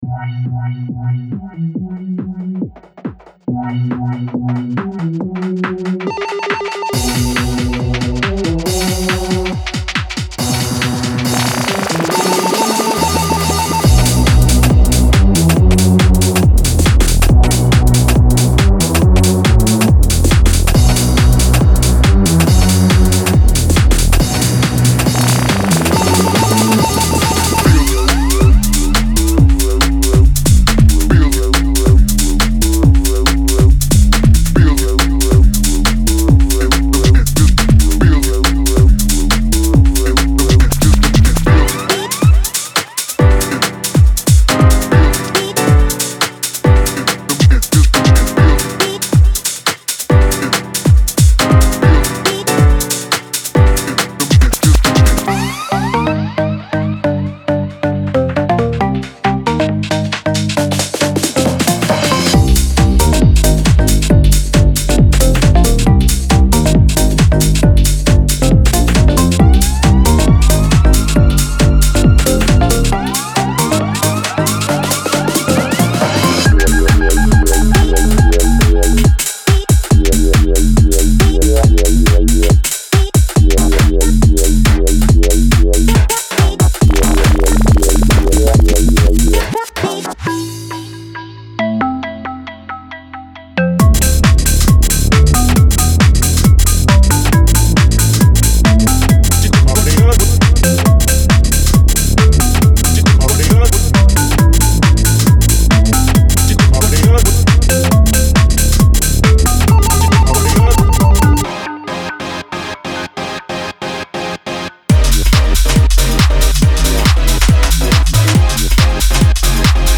Genre:Garage
スライドや動き、アティチュードを備えた、重厚でローエンド重視のベースラインです。
クラシックなガラージにインスパイアされたオルガンコードとリフを収録しています。
深くムーディーなパッドで、空間と雰囲気を演出するために設計されています。
チョップやプロセッシングが施されたボーカルフレーズとテクスチャーを収録しています。
デモサウンドはコチラ↓